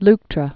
(lktrə)